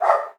dog_bark_small_03.wav